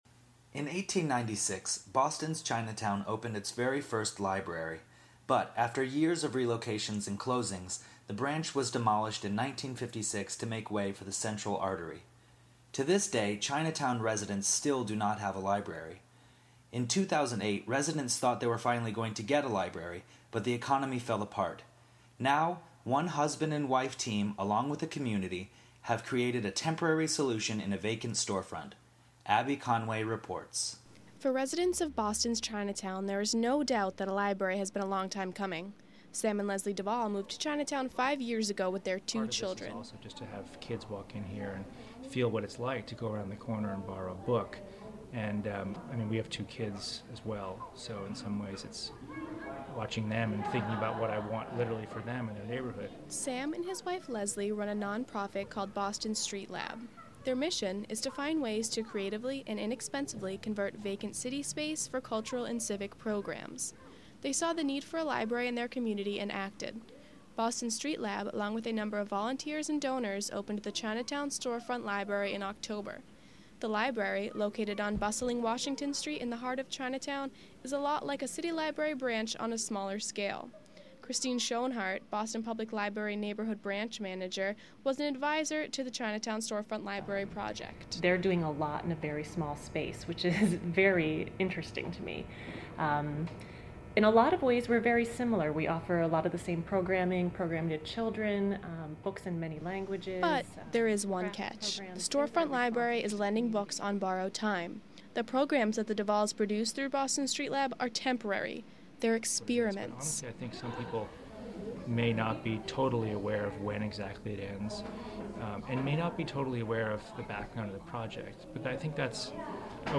Radio Feature